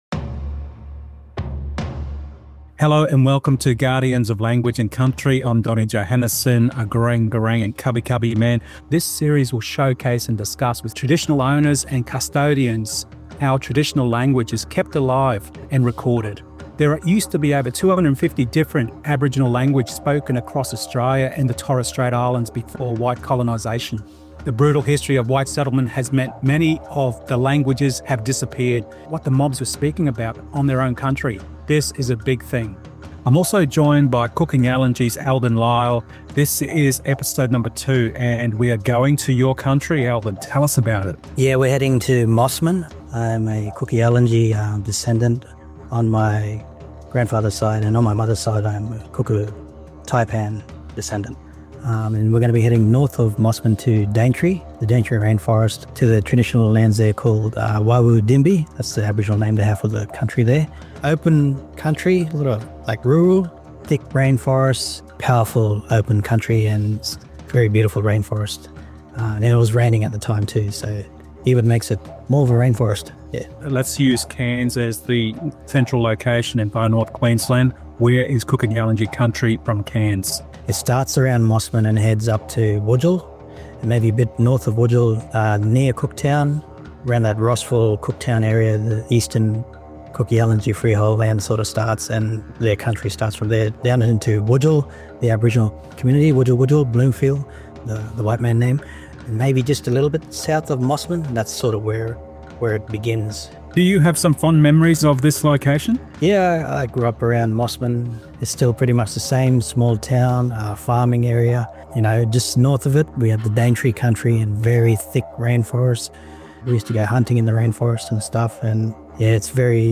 In this episode of Guardians of Language and Country, we journey through the dense rainforests of the Daintree to Mossman, on Kuku Yalanji Country.